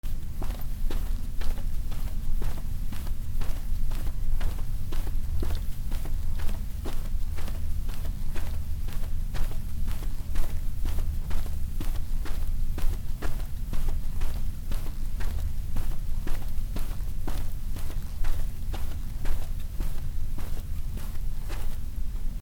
足音 じゅうたん
/ I｜フォーリー(足音) / I-240 ｜足音 特殊1
『スタスタ』